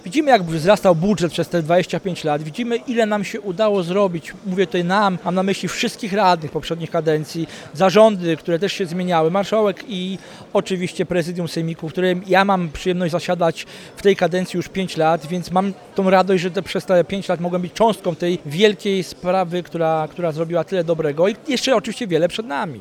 Wiceprzewodniczący sejmiku województwa, Marcin Podsędek mówi, że cały samorząd może być dumny z tego co udało się osiągnąć.